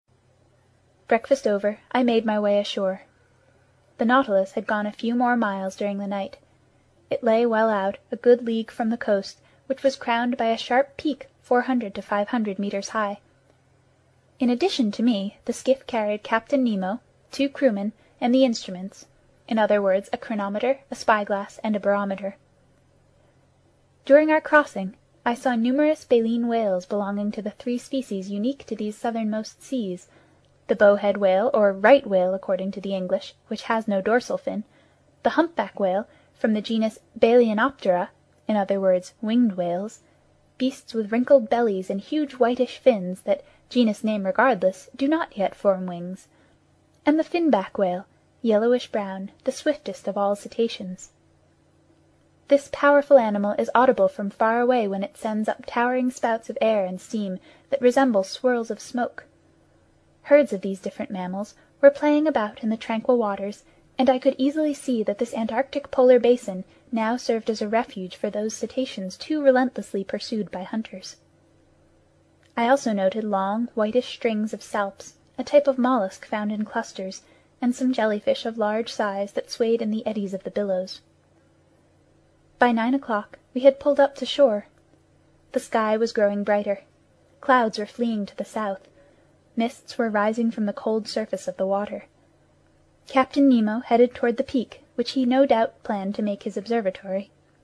在线英语听力室英语听书《海底两万里》第455期 第27章 南极(14)的听力文件下载,《海底两万里》中英双语有声读物附MP3下载